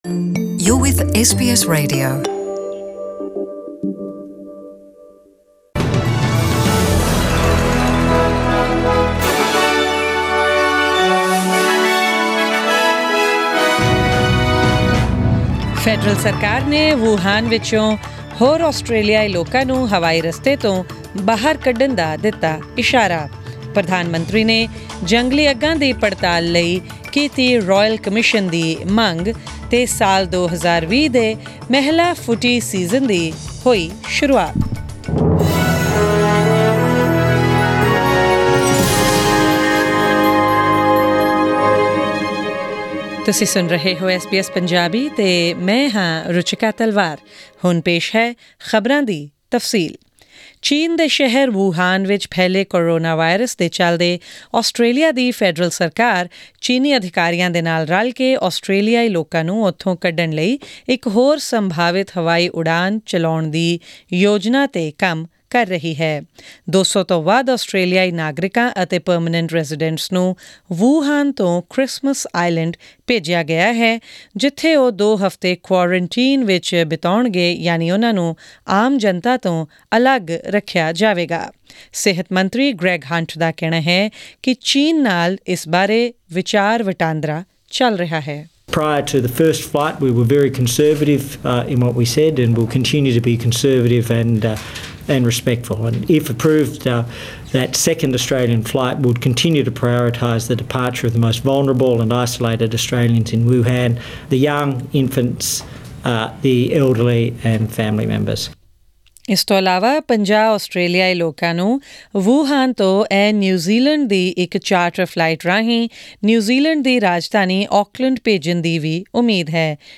Australian News in Punjabi: 4 February 2020